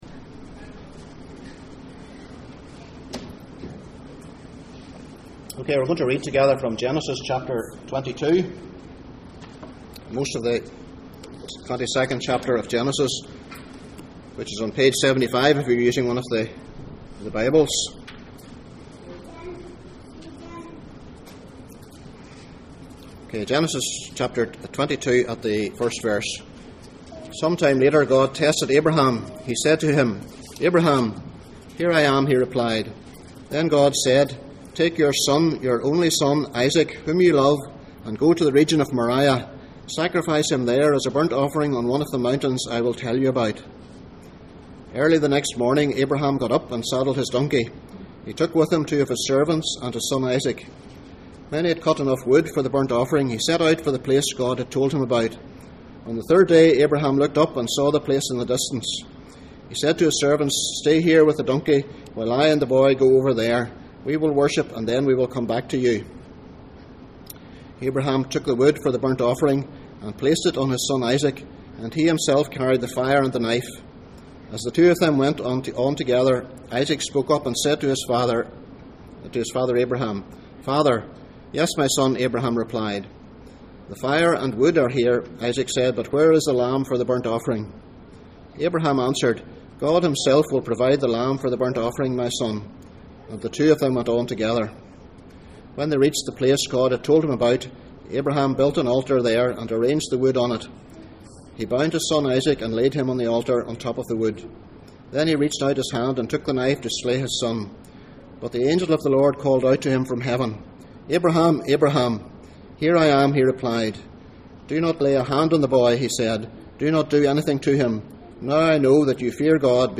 Passage: Genesis 22:1-19, Hebrews 11:17-19, Romans 8:31-32 Service Type: Sunday Morning %todo_render% « Is there a future in history Where can wisdom be found?